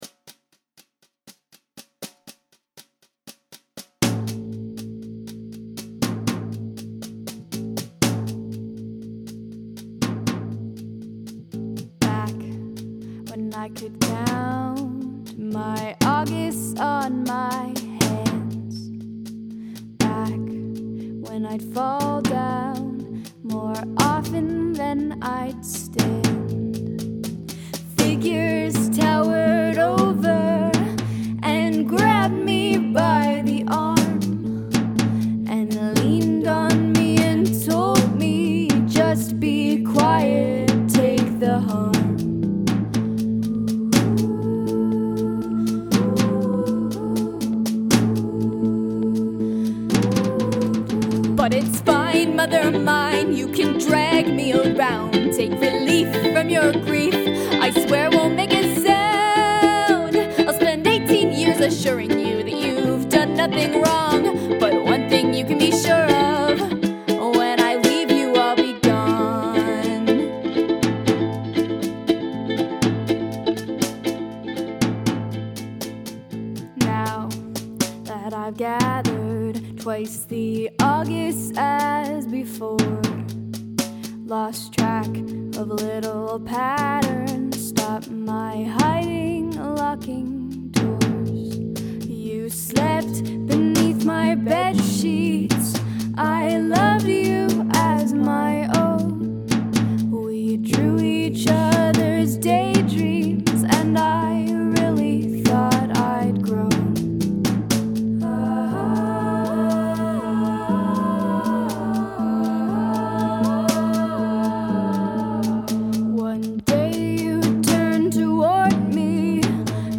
Nice ukulele tone on the chorus.